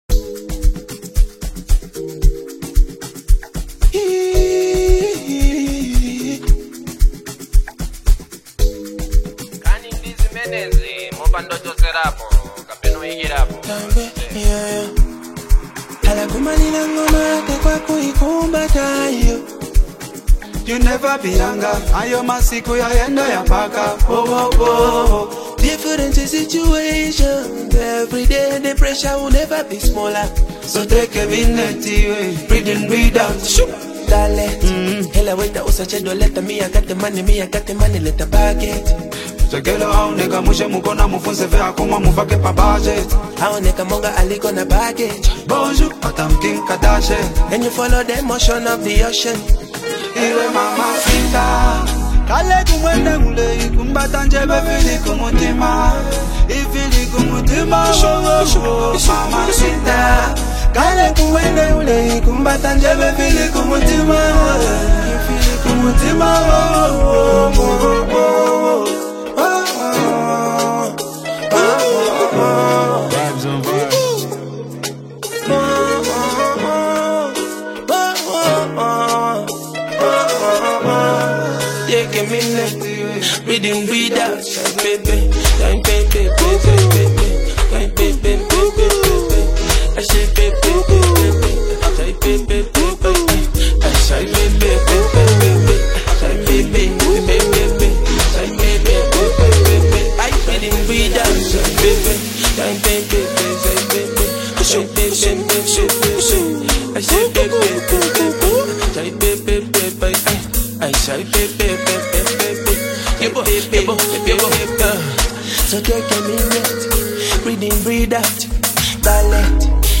Genre: Afro-Pop